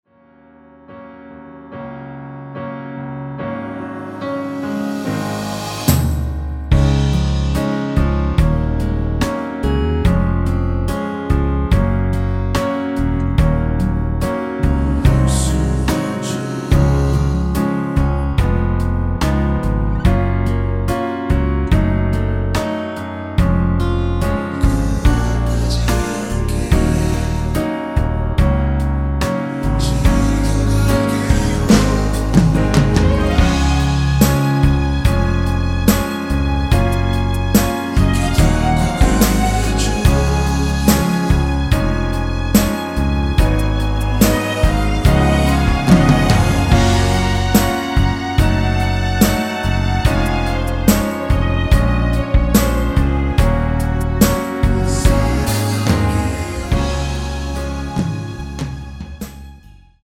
원키에서(-2)내린 코러스 포함된 MR입니다.(미리듣기 확인)
Db
앞부분30초, 뒷부분30초씩 편집해서 올려 드리고 있습니다.